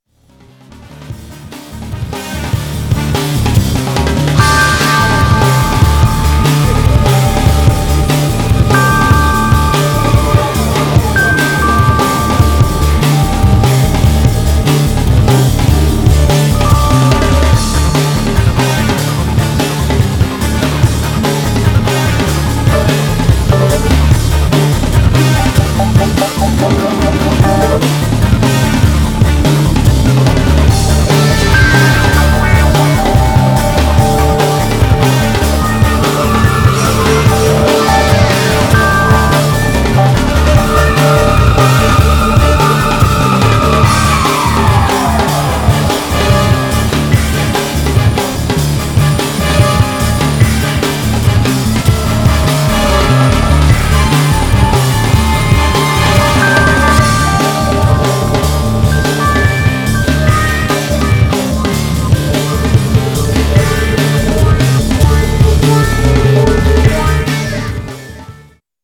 Styl: Drum'n'bass, Trance